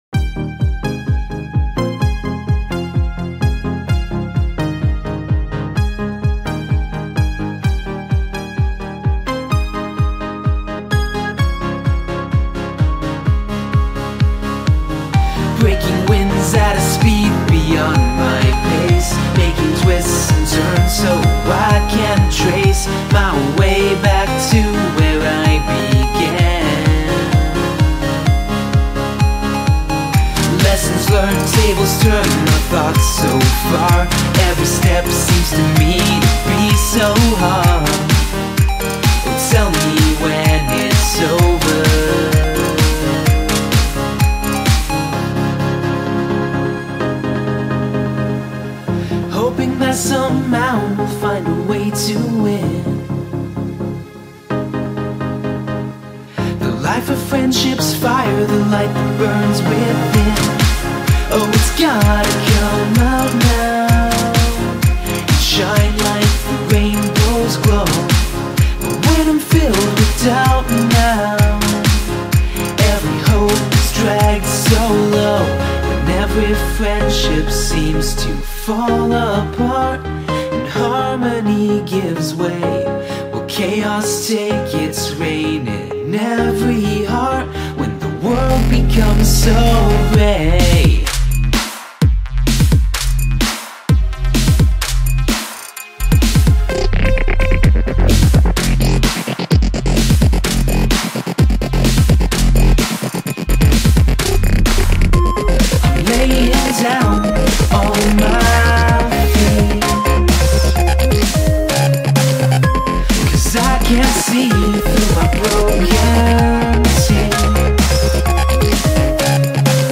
A true friend and a brilliant vocalist.